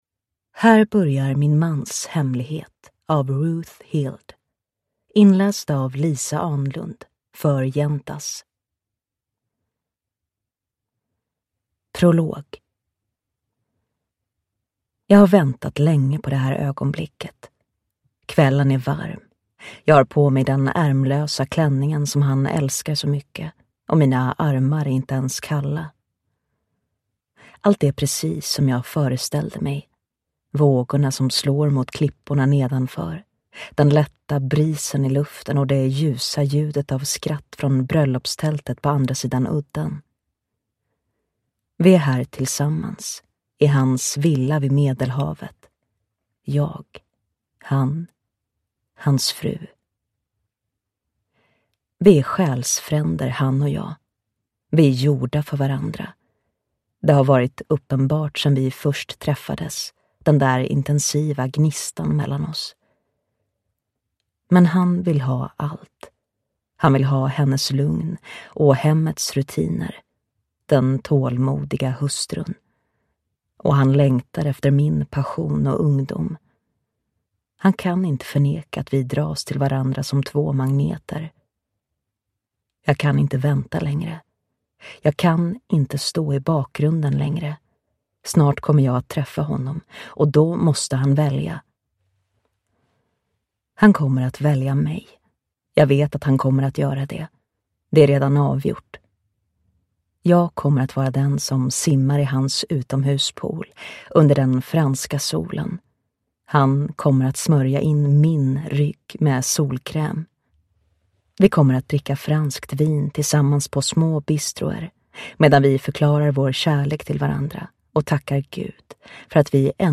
Min mans hemlighet – Ljudbok